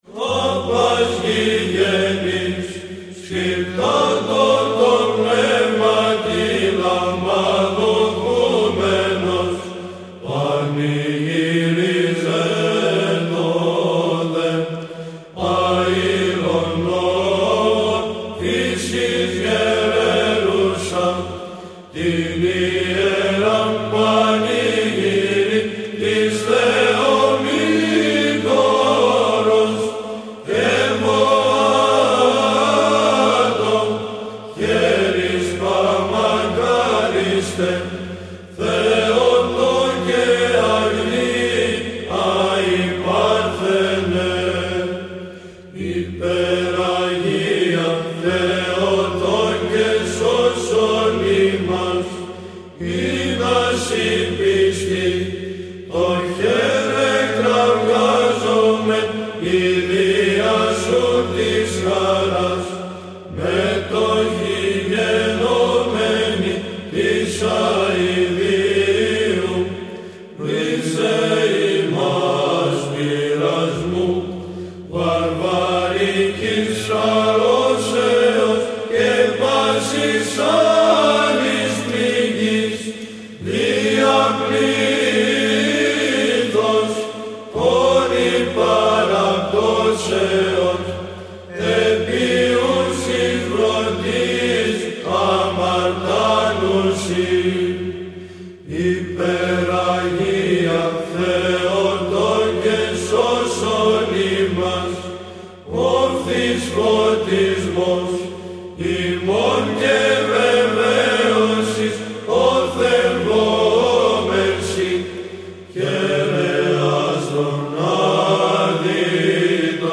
από δύο αντιπροσωπευτικές Βυζαντινές χορωδίες
Ωδή Θ. – ψάλλει ο Βυζαντινός Χορός των Τρικάλων